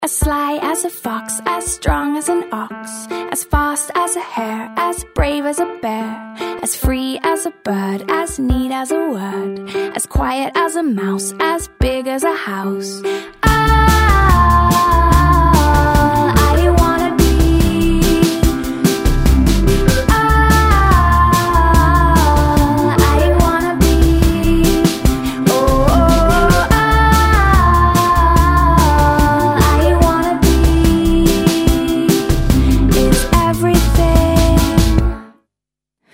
• Качество: 128, Stereo
OST
из рекламы